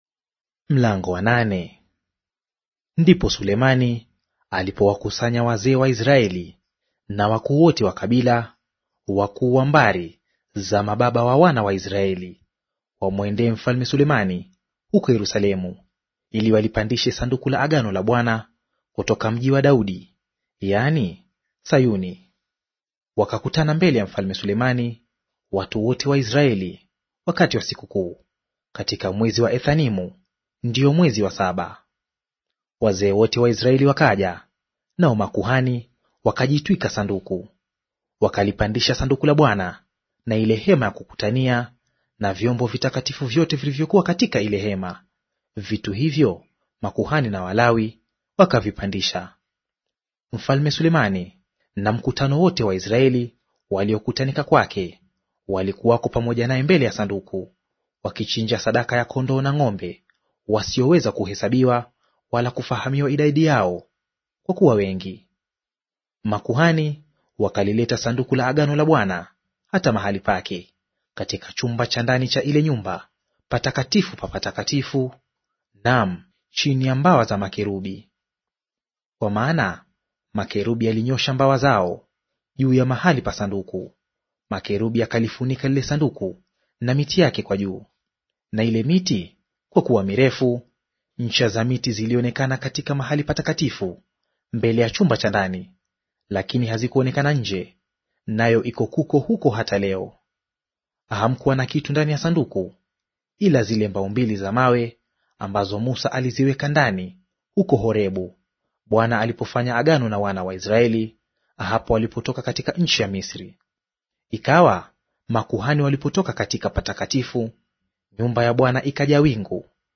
Audio reading of 1 Wafalme Chapter 8 in Swahili